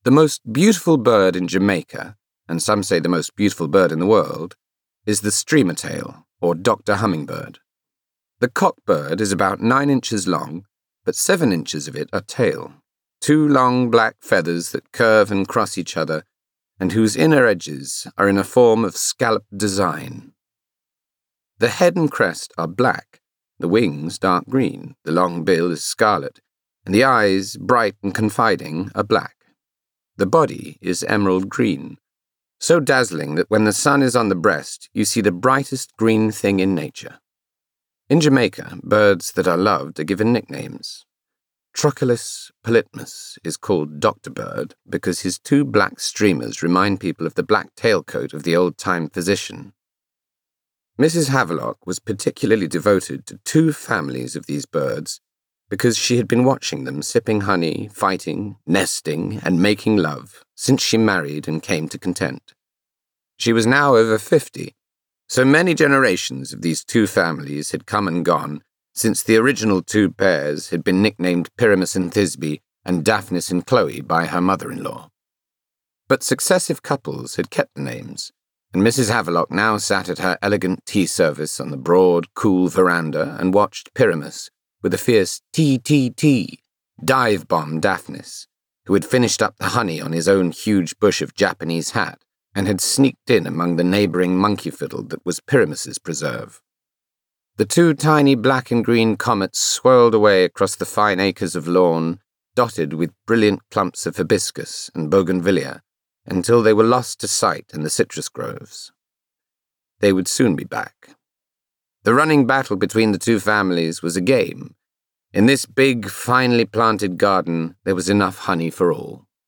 Neutral
Male
Friendly
Soft
FOR YOUR EYES ONLY AUDIOBOOK
Johnny Flynn_For Your Eyes Only Audiobook_UV.mp3